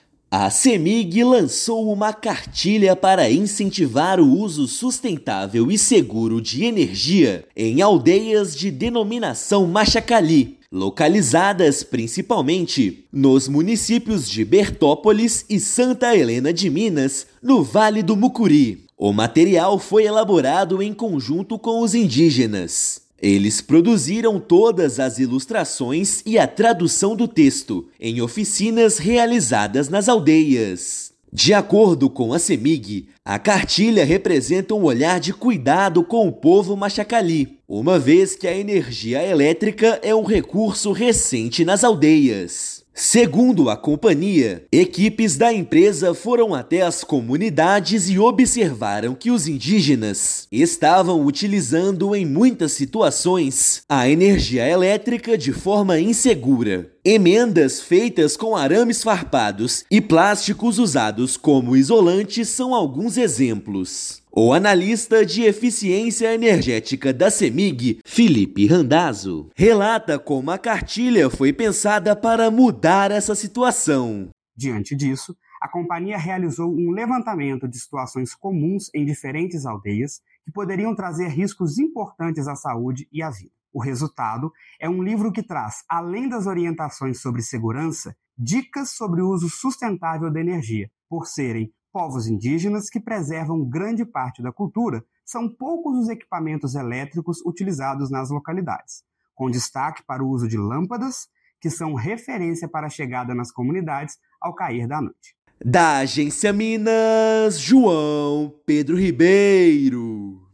Material tem como objetivo garantir a segurança e o uso sustentável da energia nas aldeias. Ouça matéria de rádio.